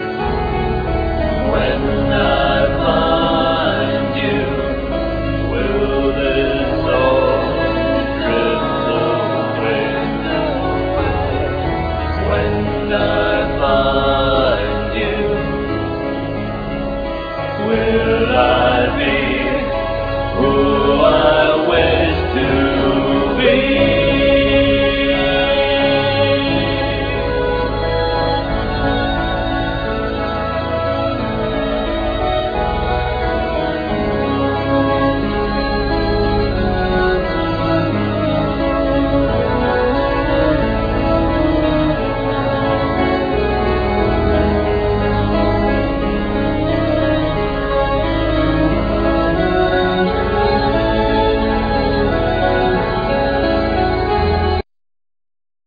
Disc 1(Vocal Tracks)
Vocals,Viola
Flute
Cello
Violin
Electronics